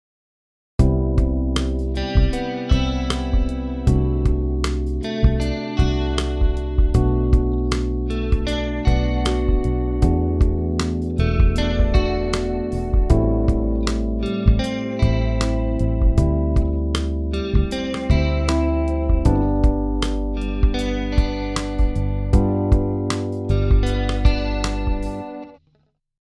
チャンネル3と4のディレイタイムを長めに設定してみました。さらに深いうねりのモジュレーションが得られるようになりましたね。